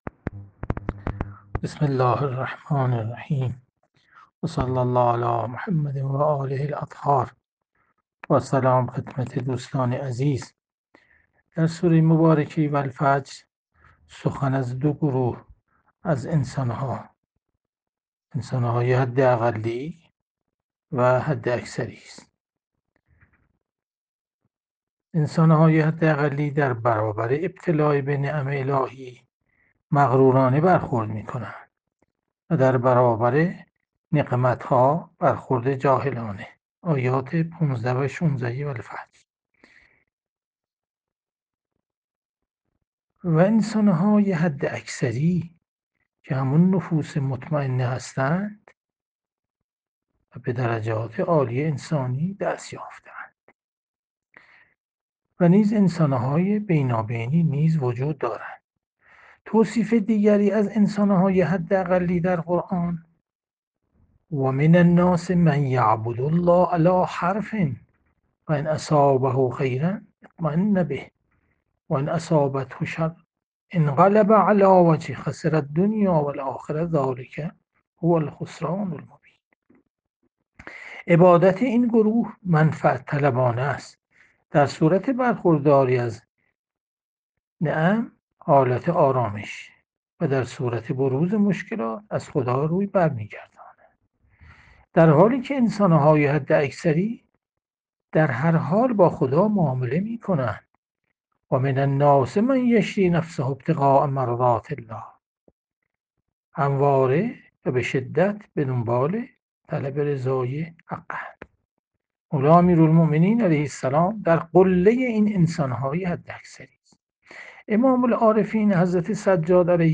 جلسه مجازی هفتگی قرآنی، سوره فجر، 01 خرداد 1401
• تفسیر قرآن